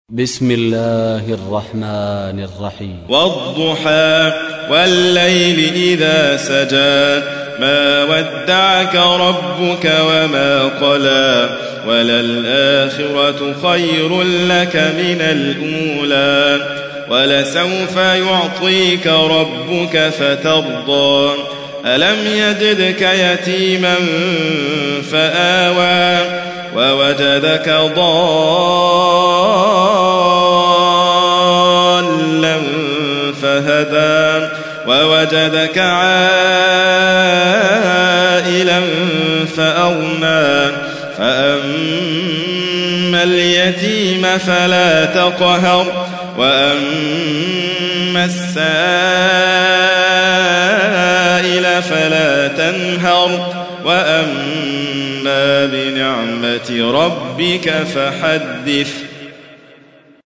تحميل : 93. سورة الضحى / القارئ حاتم فريد الواعر / القرآن الكريم / موقع يا حسين